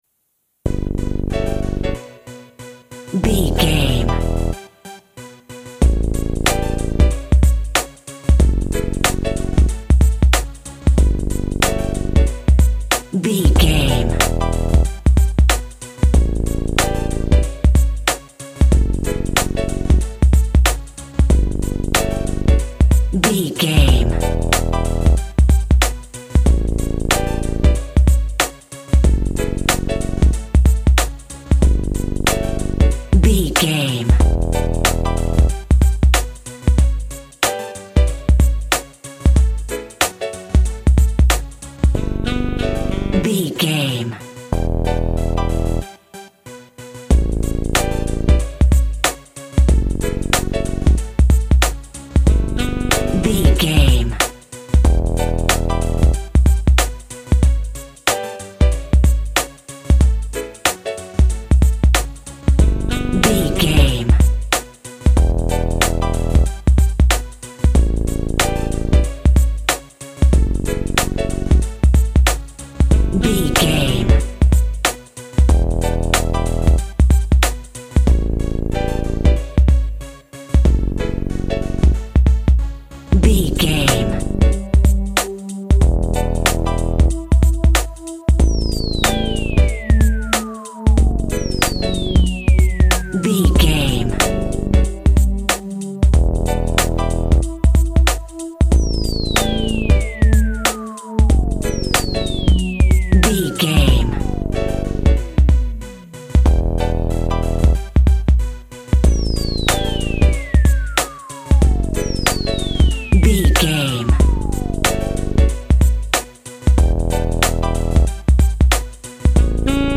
Aeolian/Minor
synth lead
synth bass
hip hop synths
electronics